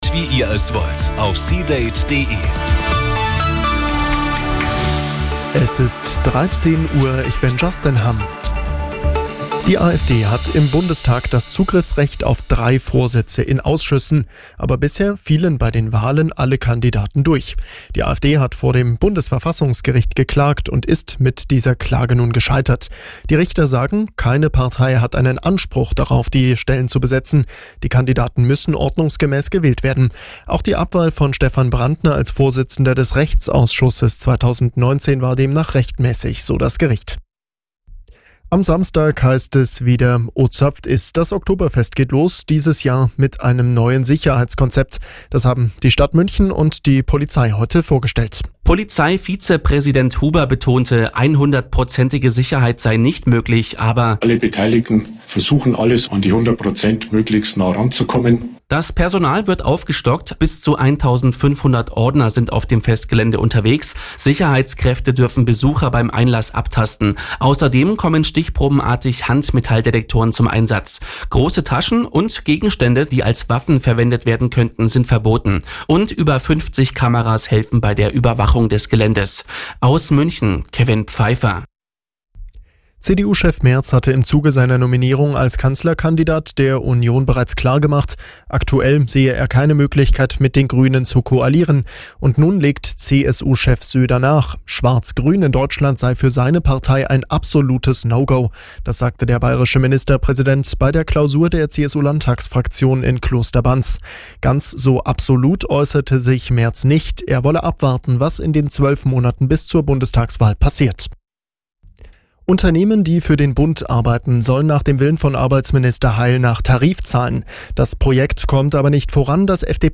Die Radio Arabella Nachrichten von 16 Uhr - 18.09.2024